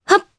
Priscilla-Vox_Jump_jp.wav